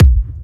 Bassdrums
ED Bassdrums 33.wav